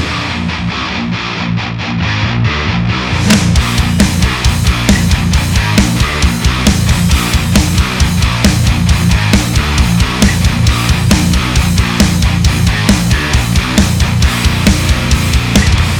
Классный звук.